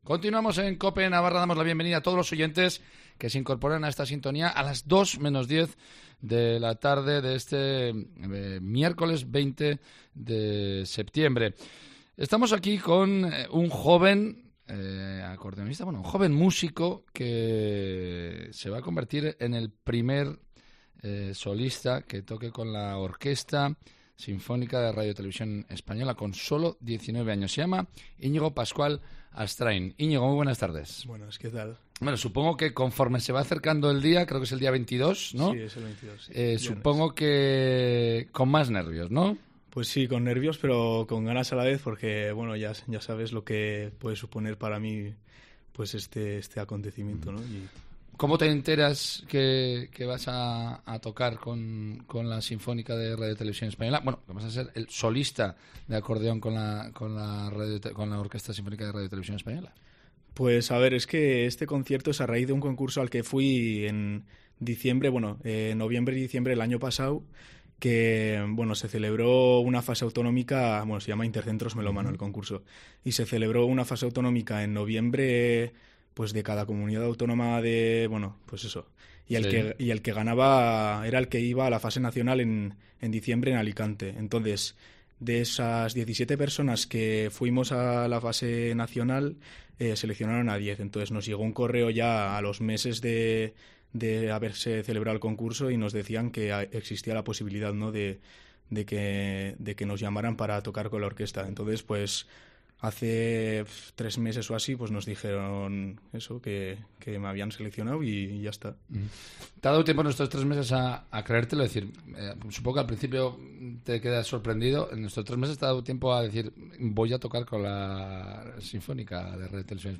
Este joven músico de 19 años ha pasado por los micrófonos de Cope Navarra para explicar las sensaciones que tiene justo antes de viajar a Madrid para grabar con la Orquesta Sinfónica de RTVE.